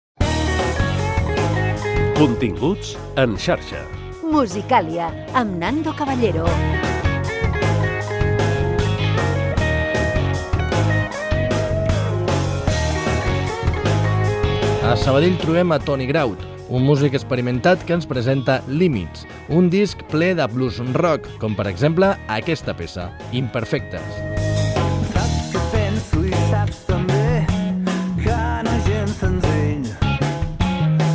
Careta del programa i presentació d'un tema musical
Musical